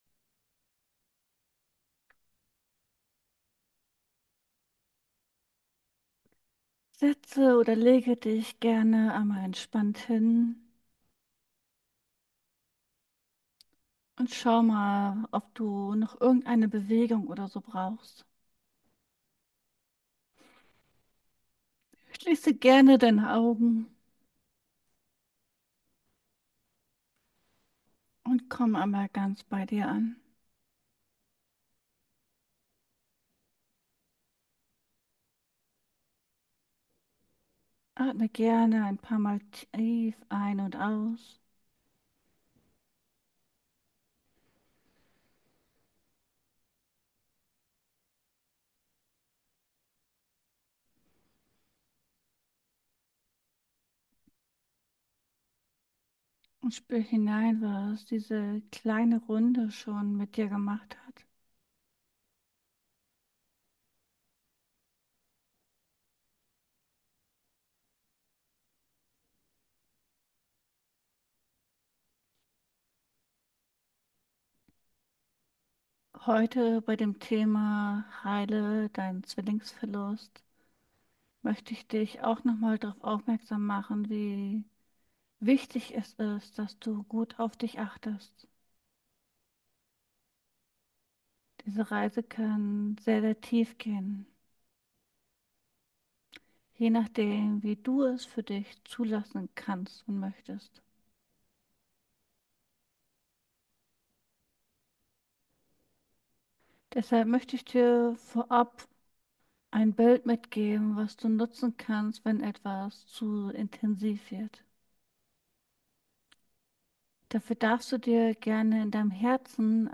Zwillingsverlust – Eine geführte Reise zur Selbstentdeckung ~ Ankommen lassen Podcast
Beschreibung vor 11 Monaten In diesem bewegenden Video laden wir dich ein, auf eine transformative Reise zu gehen, um den Verlust eines Zwillings zu verarbeiten und zu reflektieren. **Was erwartet dich?** Erlebe eine geführte Meditation, die dich dazu anregt, dich mit deinen inneren Gefühlen und Erinnerungen auseinanderzusetzen.